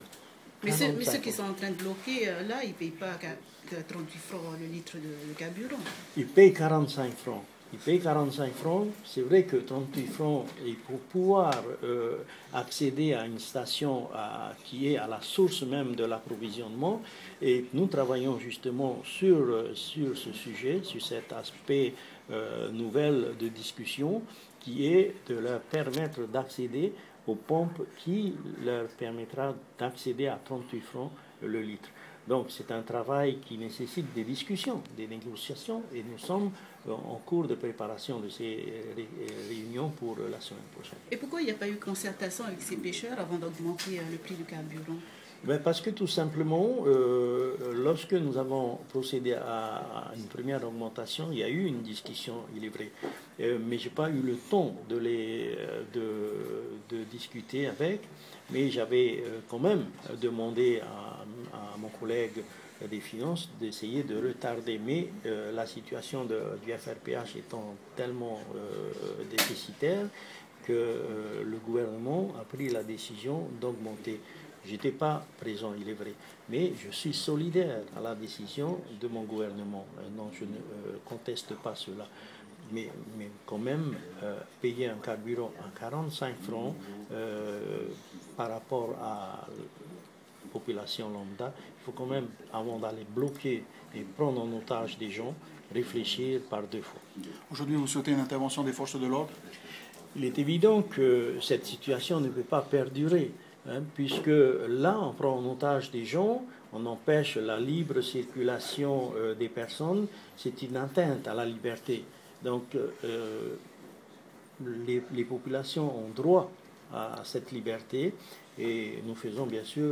Ecouter l'interview audio du ministre: Mémo (2).m4a (1.45 Mo)